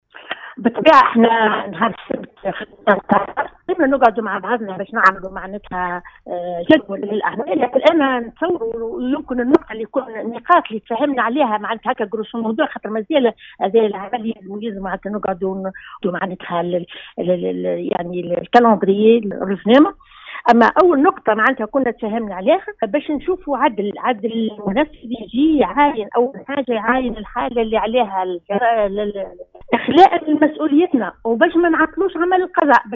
في اتصال بإذاعة السيليوم أف أم صباح اليوم